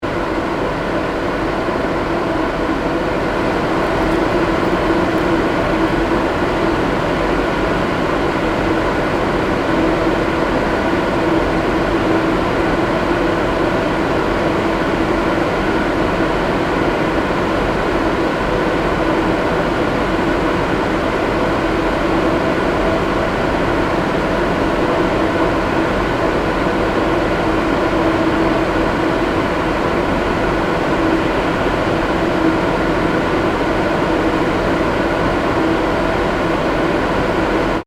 Soundscape: La Silla ESO 3.6-metre-telescope chiller
The sound of fans inside the telescopes is an iconic soundscape. This is how a typical night sounds inside the dome of the ESO 3.6-metre telescope building. The noise of the chillers can also be heard from the control room cameras, and even the astronomers observing remotely can listen to it through the computer while connecting with the on site telescope and instrument operator (TIO).
ss-ls-36-chiller_stereo.mp3